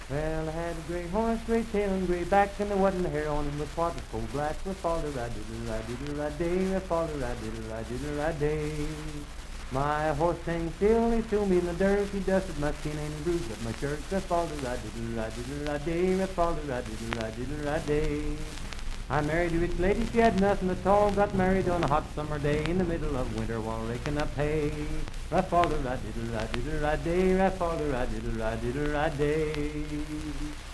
Unaccompanied vocal music
Humor and Nonsense, Dance, Game, and Party Songs
Voice (sung)
Wood County (W. Va.), Parkersburg (W. Va.)